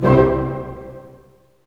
Index of /90_sSampleCDs/Roland L-CD702/VOL-1/HIT_Dynamic Orch/HIT_Orch Hit Maj
HIT ORCHM03R.wav